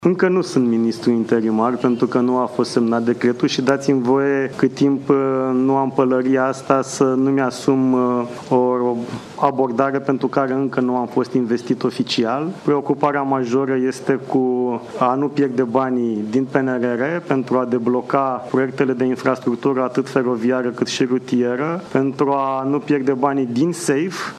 Banii din PNRR și programul SAFE sunt printre cele mai importante obiective în prezent, a declarat ministrul Apărării, în ultima zi a exercițiului NATO „Eastern Phoenix”, din Poligonul Capu Midia, județul Constanța.
Ministrul Apărării, Radu Miruță: „Preocuparea majoră este de a nu pierde banii”